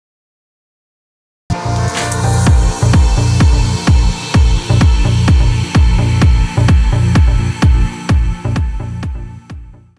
Как сделать такой вокальный подклад с таким длинным ревербиционным хвостом?
Вложения Vocal FX.wav Vocal FX.wav 3,4 MB · Просмотры: 180